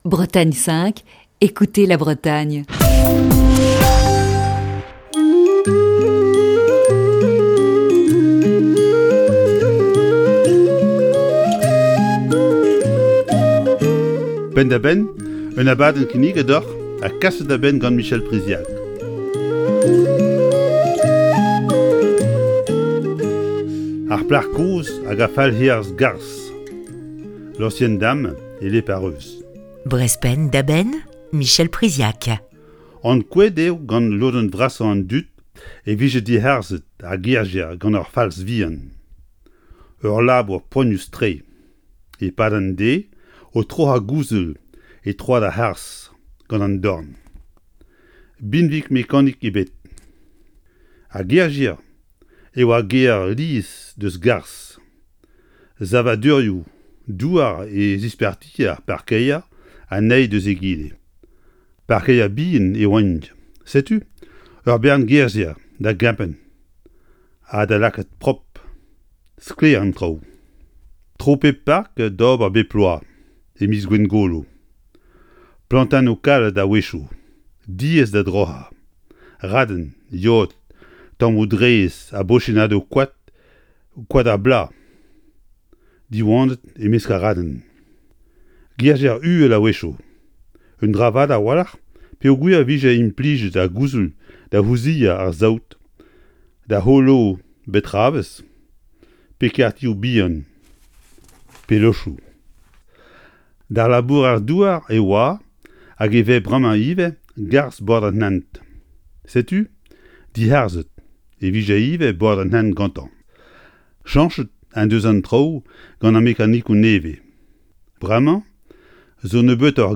Chronique du 15 juin 2020.